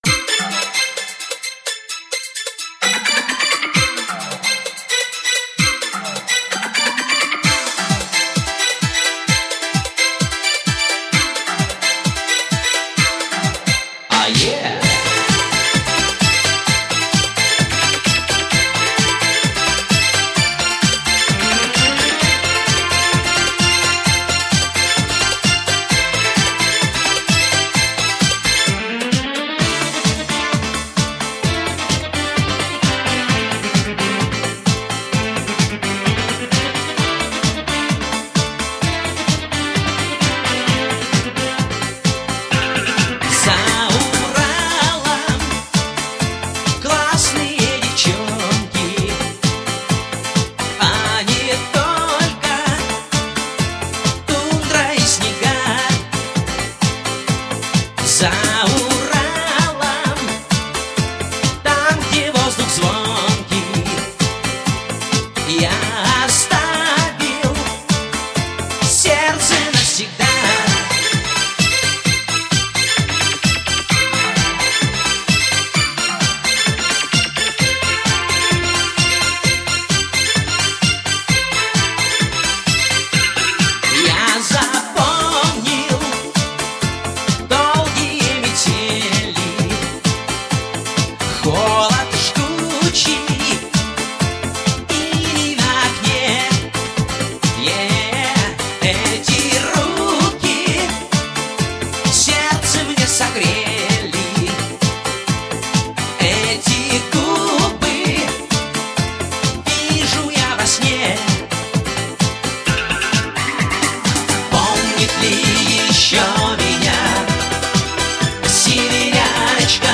Жанр: поп, поп-музыка